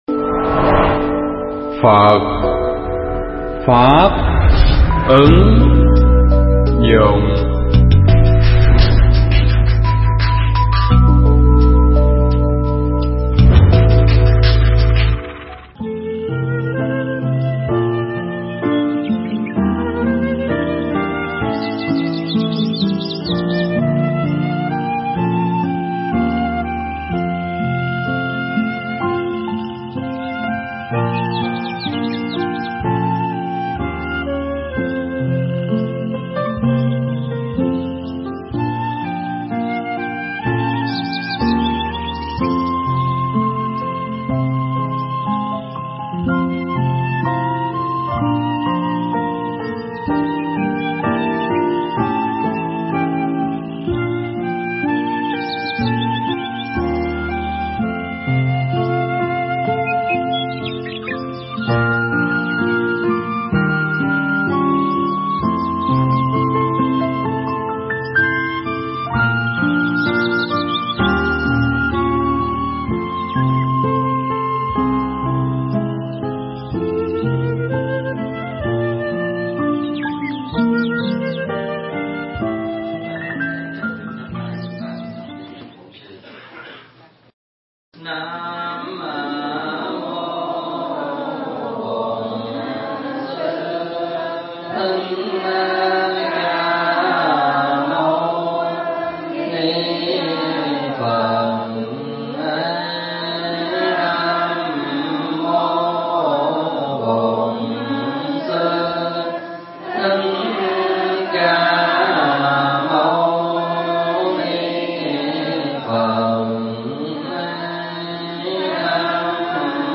thuyết pháp Bài Học Về Lòng Từ Bi
giảng tại chùa Quan Âm (Canada)